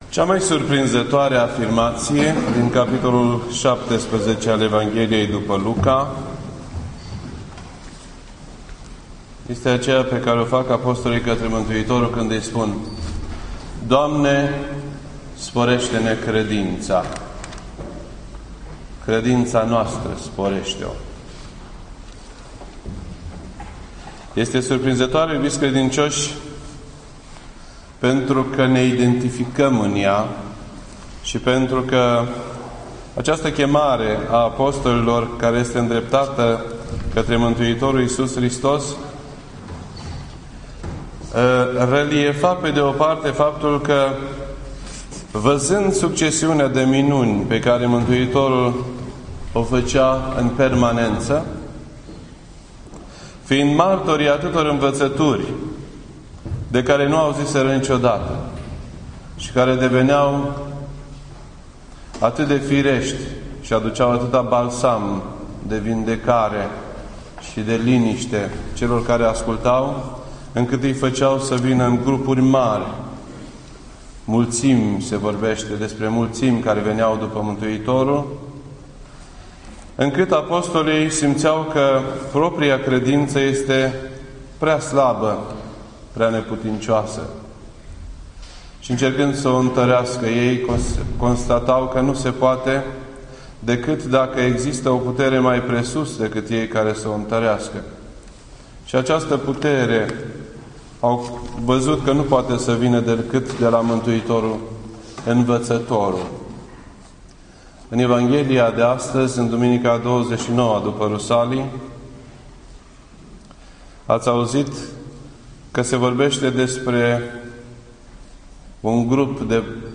This entry was posted on Monday, January 2nd, 2012 at 8:53 PM and is filed under Predici ortodoxe in format audio.